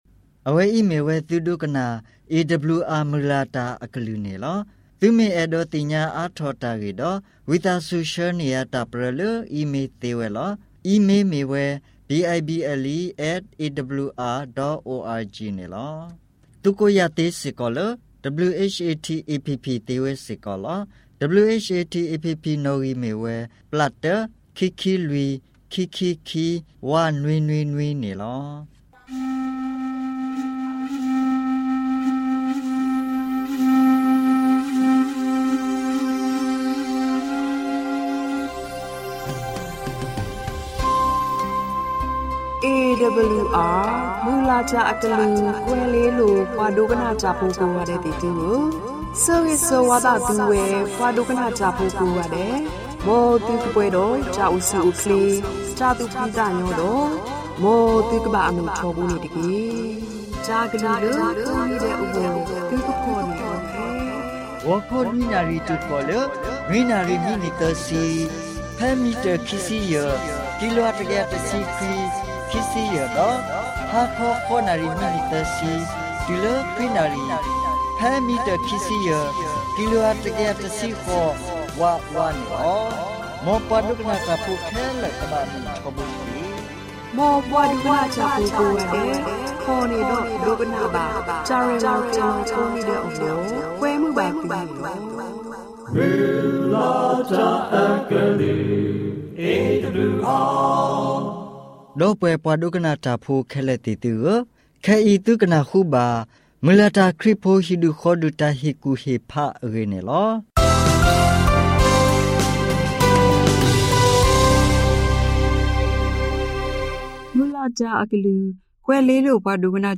Karen radio program by Adventist World Radio